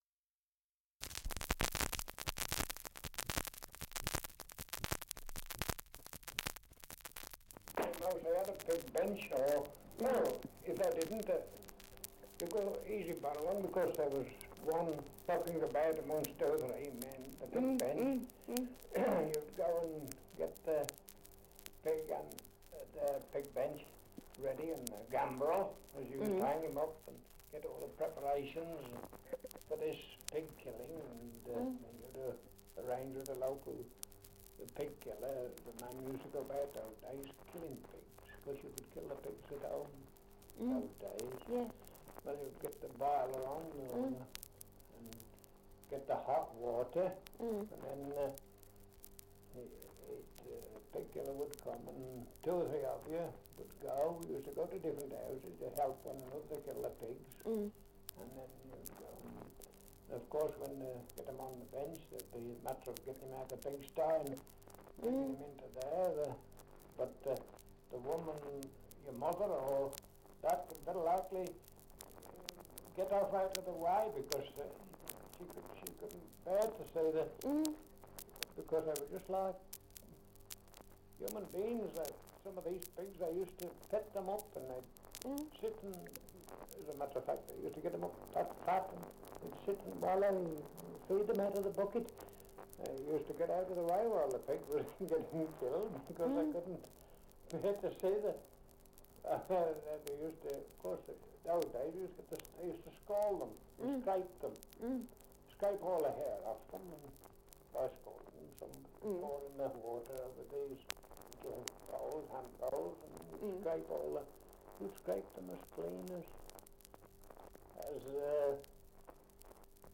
Dialect recording in Albrighton, Shropshire
78 r.p.m., cellulose nitrate on aluminium